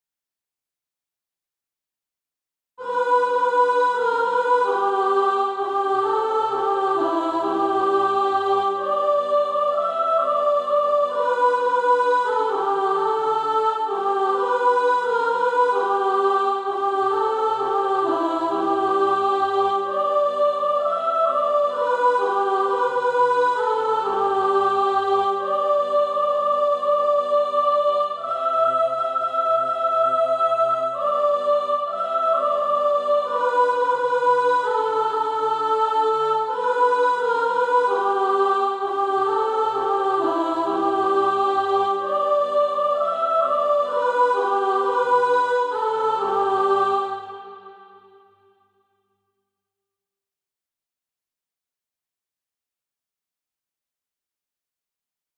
(SATB) Author
Soprano Track.
Practice then with the Chord quietly in the background.